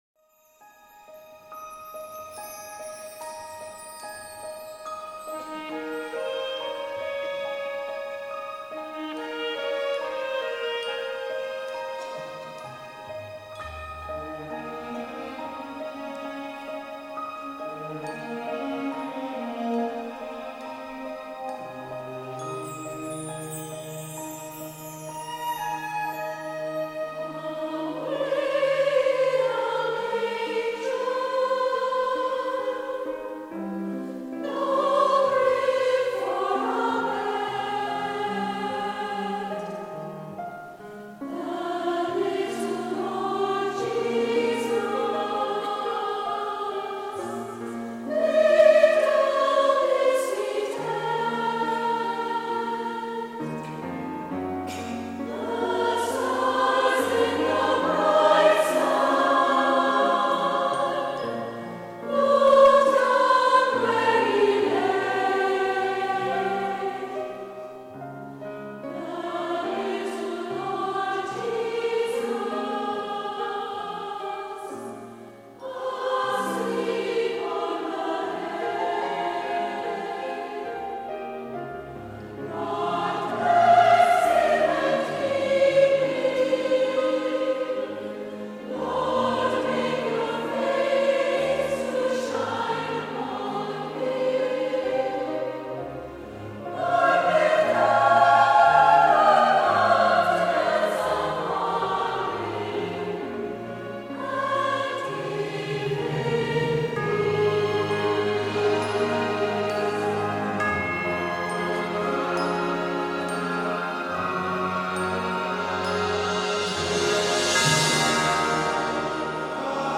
Voicing: SATB And Piano